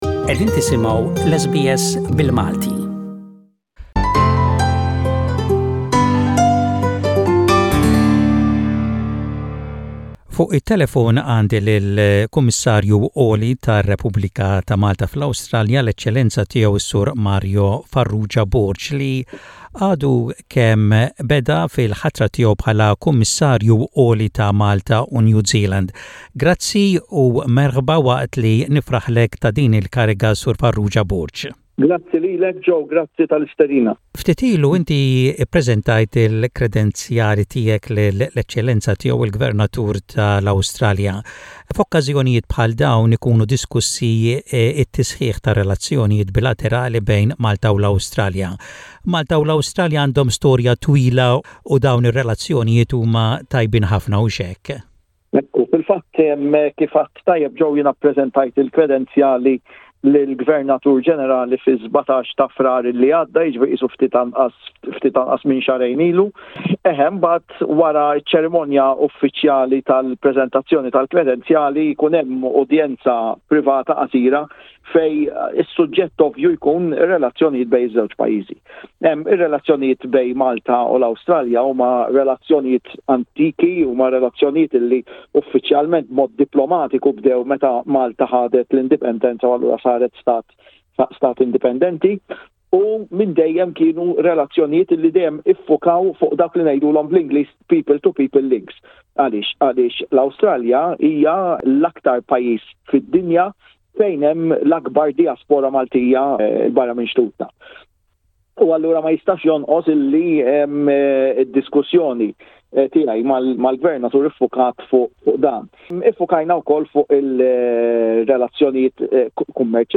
His Excellency Mr Mario Farrugia Borg, the new Maltese High Commissioner of Malta in Australia presented his credentials to His Excellency General the Honourable David Hurley AC DC (Retd), Governor-General of the Commonwealth of Australia. In an interview